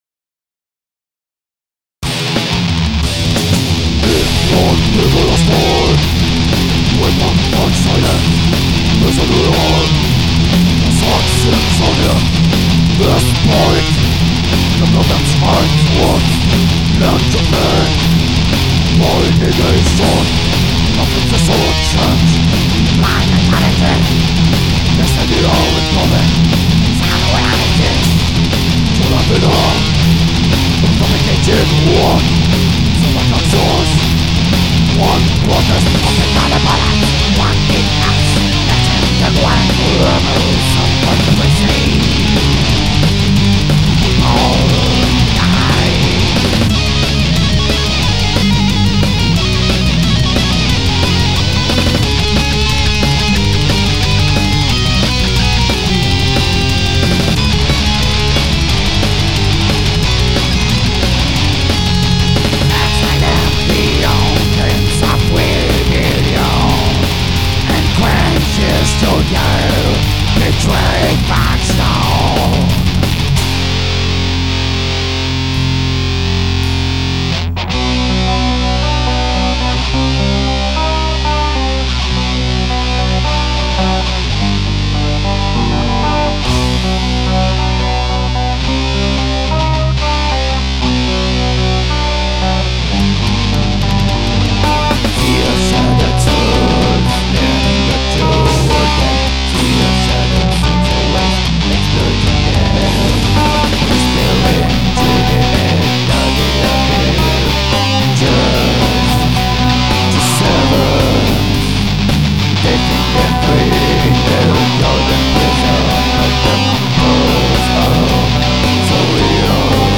DEATH / THRASH